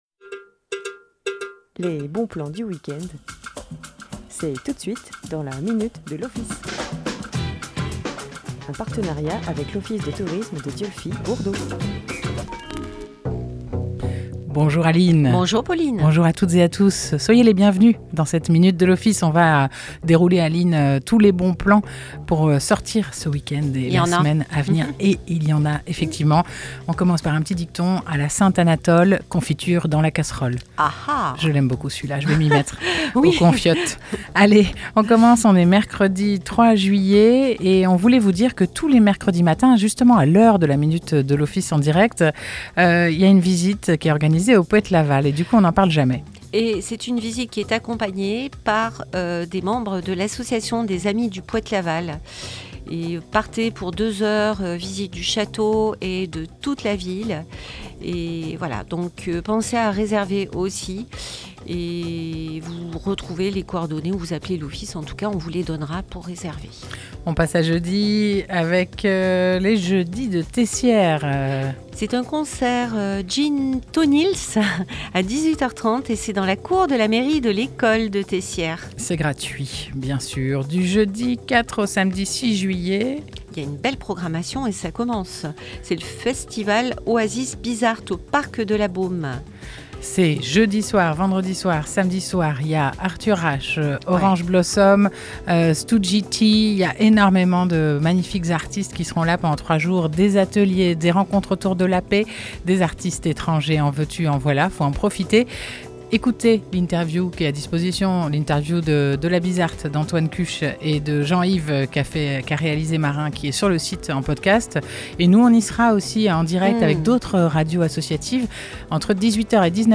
Chaque mercredi à 9h30 en direct